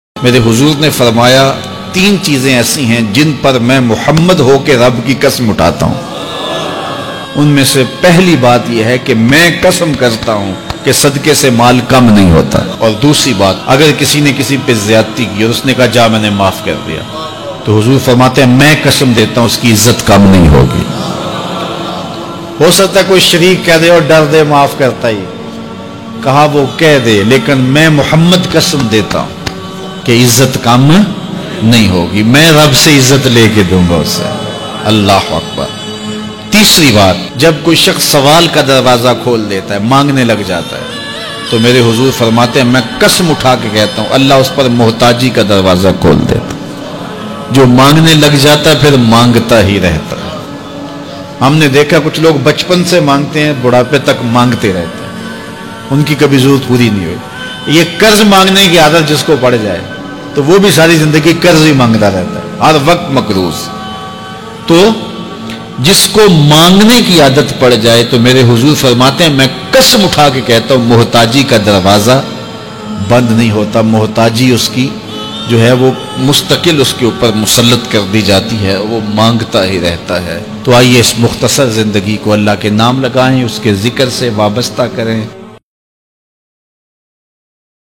Most Cryfull Bayan